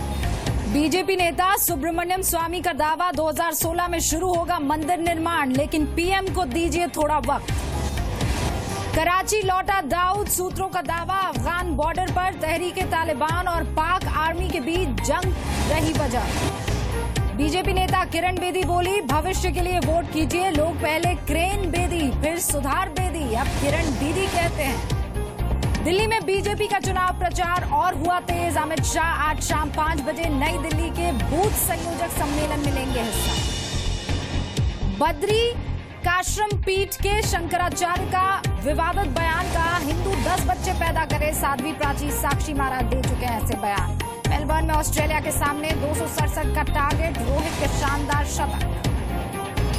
Headlines of the day